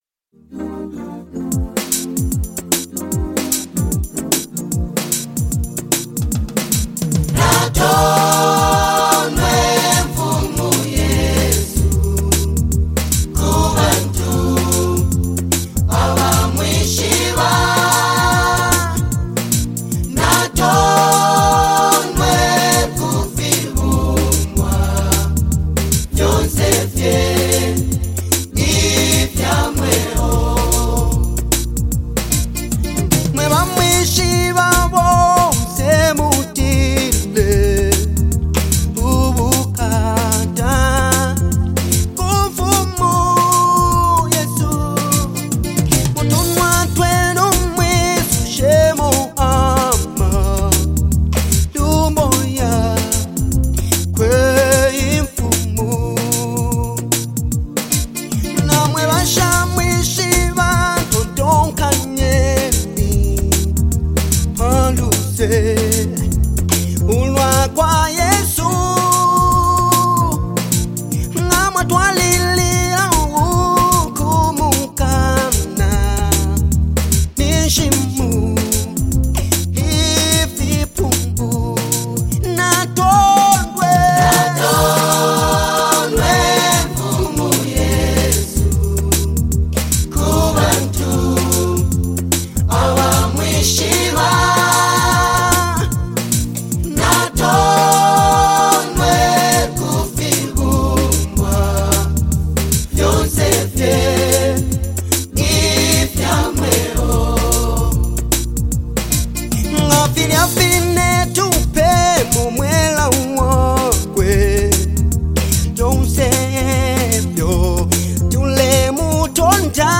soulful vocals